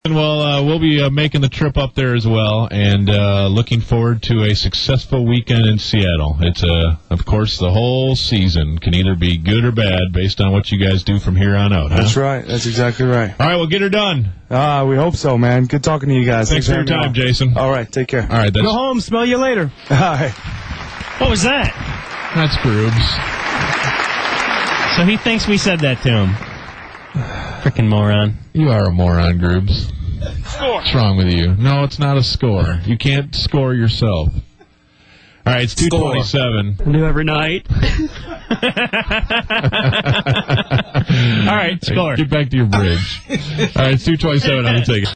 a beautiful drop at the conclusion of the interview, and hilarity ensues…